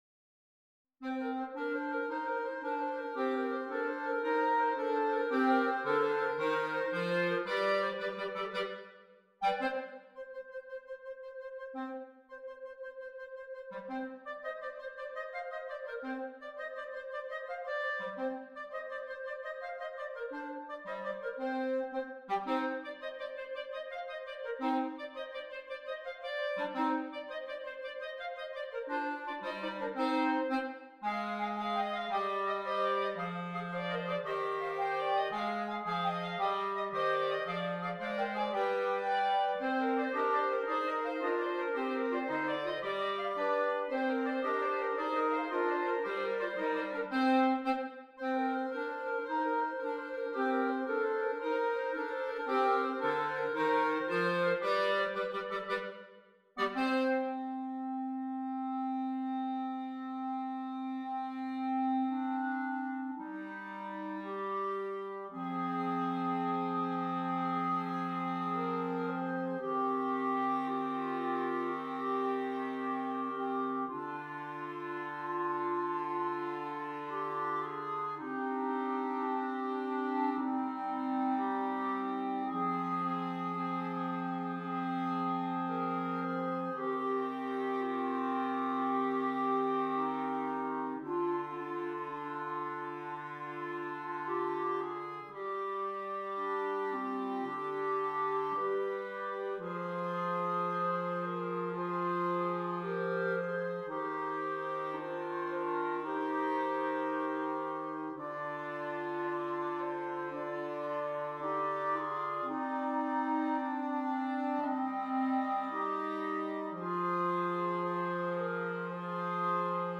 4 Clarinets